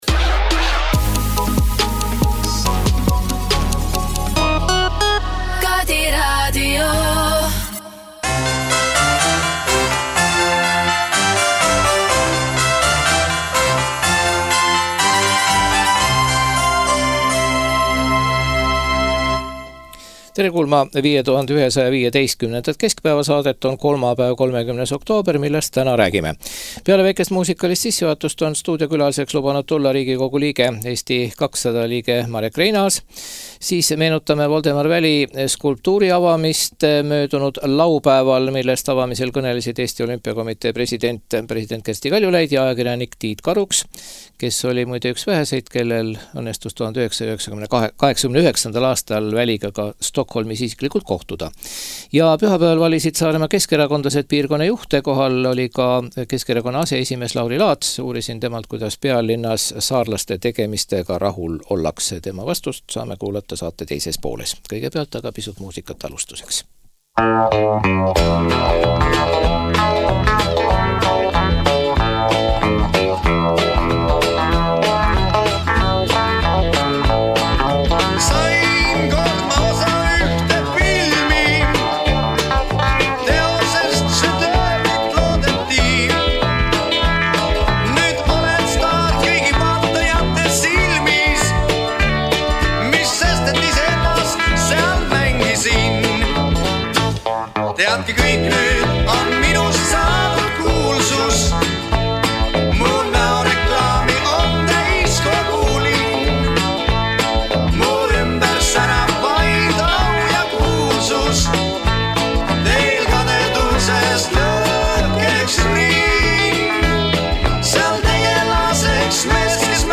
Stuudios on RK liige Marek Reinaas.